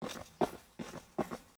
steps-running.m4a